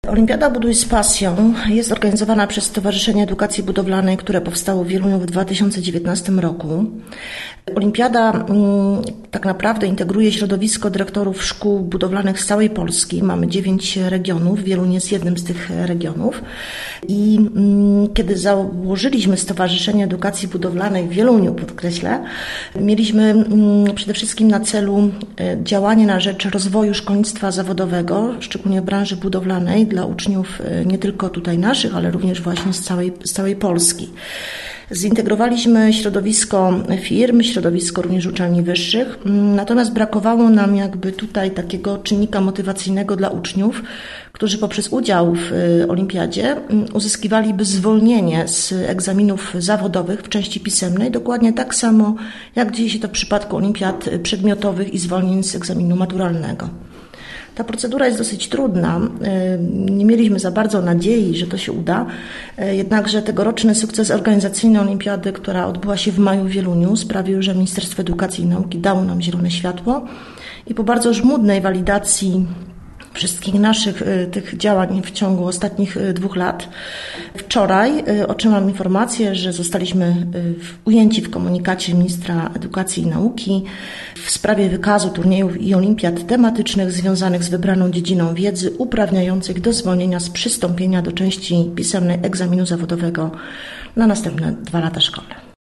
Więcej w rozmowie z naszym gościem tutaj.